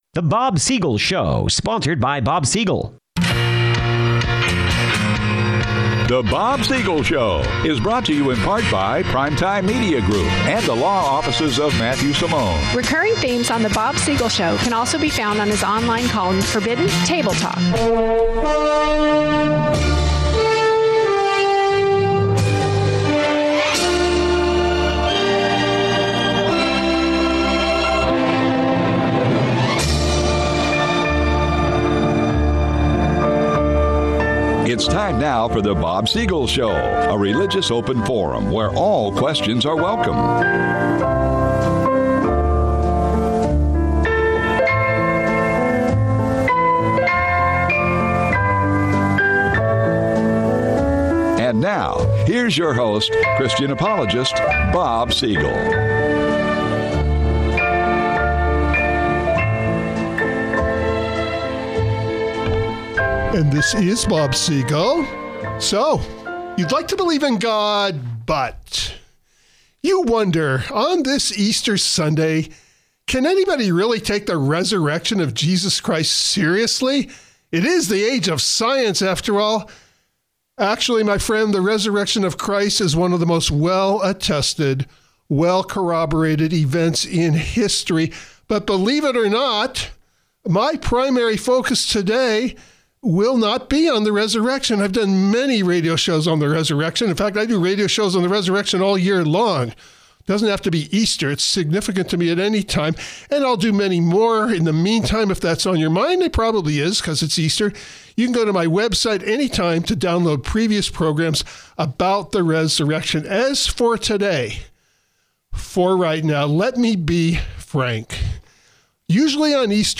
Unusual in its content, considering this program broadcast on Easter Sunday….